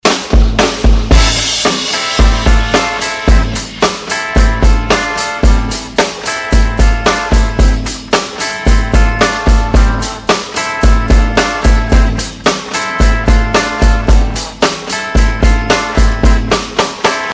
is a track with a live drum feel.